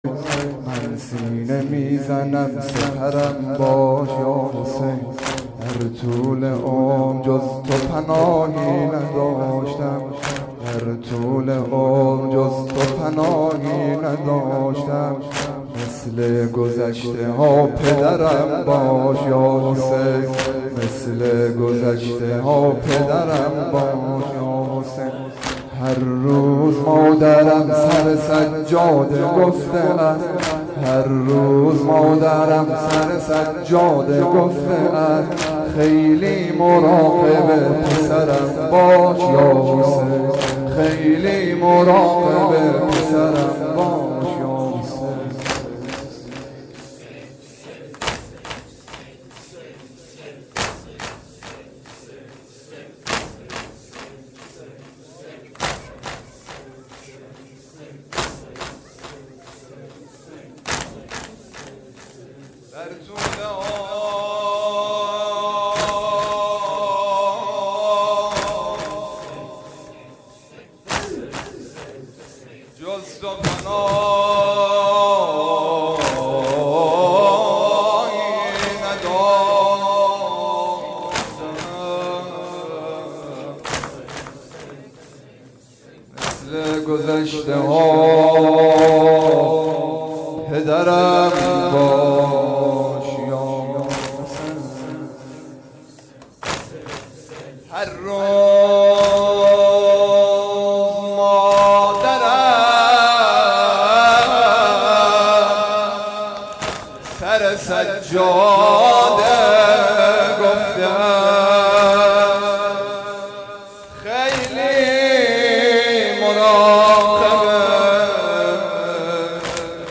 شب دوم محرم 93